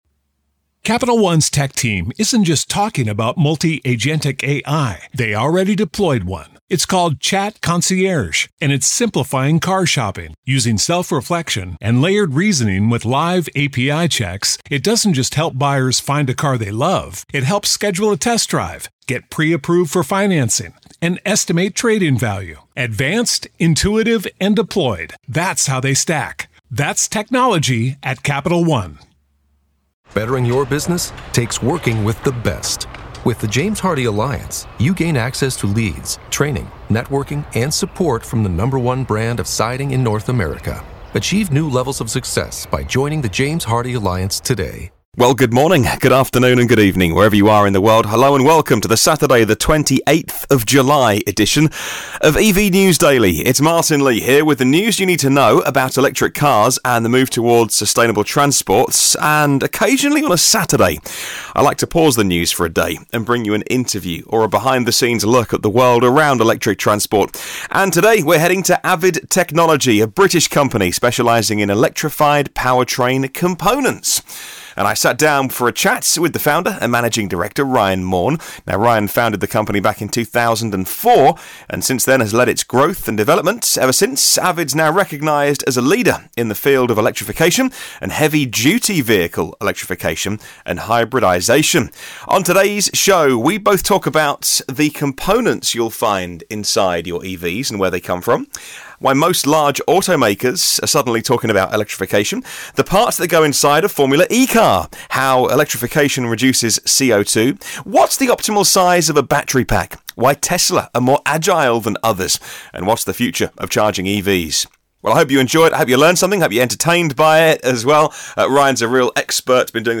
28 July 2018 | Interview: AVID Technology Electrified Powertrain Components